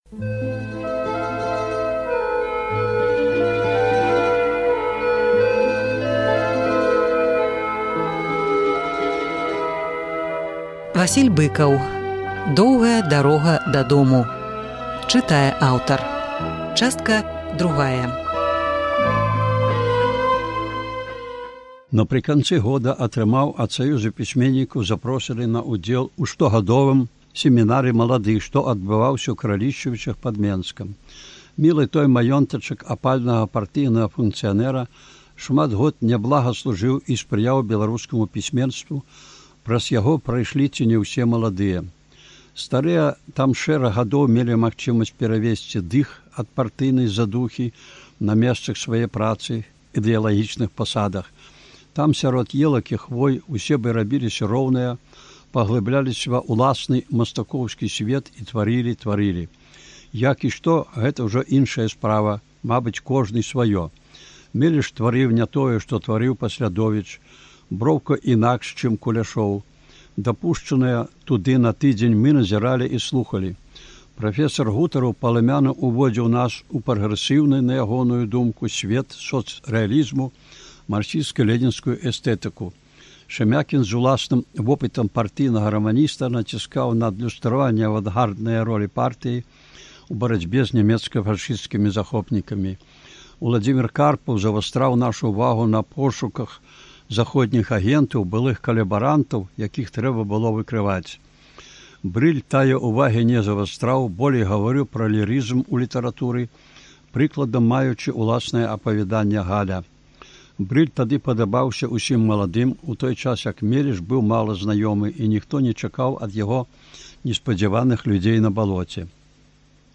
Успаміны Васіля Быкава «Доўгая дарога дадому». Чытае аўтар.